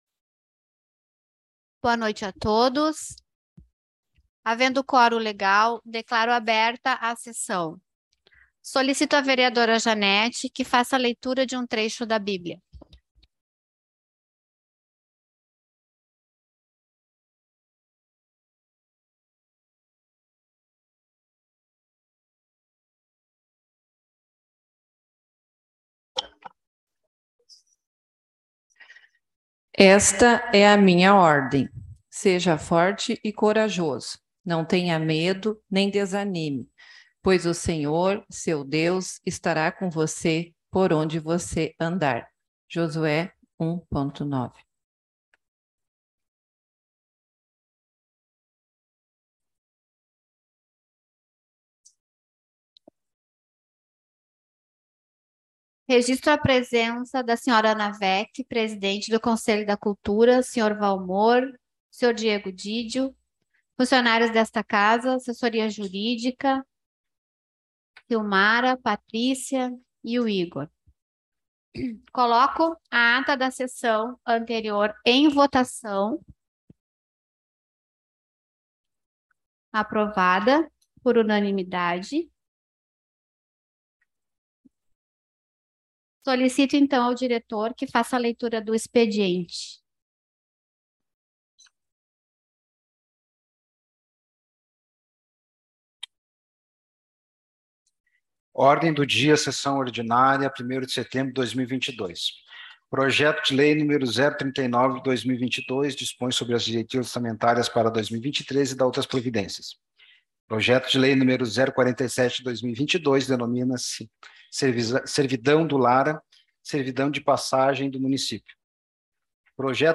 Sessão Ordinária 01.09.2022.mp3